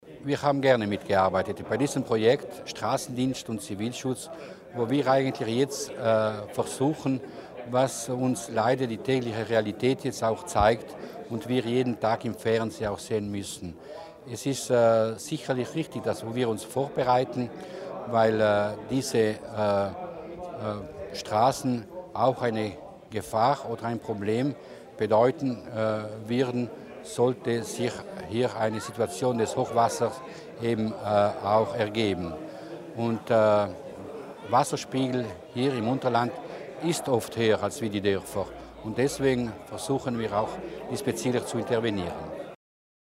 Landesrat Mussner erklärt die Wichtigkeit der Eingriffe in Kurtinig